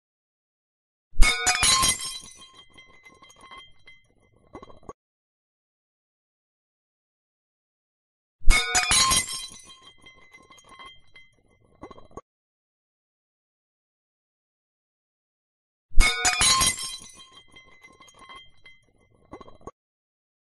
Plate Shatter / Break